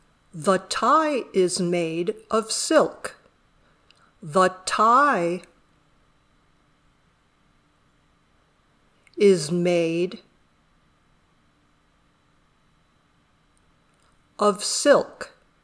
dictado
Primero vas a escuchar la oración a una velocidad normal, y luego lentamente para poder escribirla.